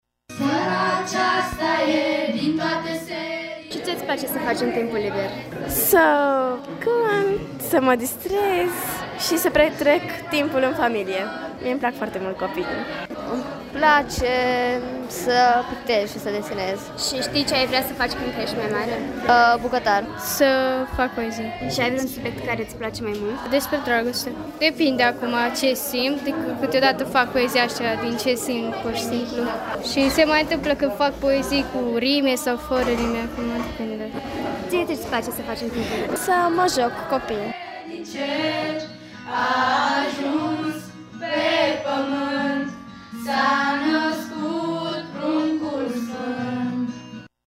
Vocile copiilor au răsunat în sala de spectacole a radioului de colinde și urări de Crăciun, care i-au impresionat plăcut pe cei prezenți: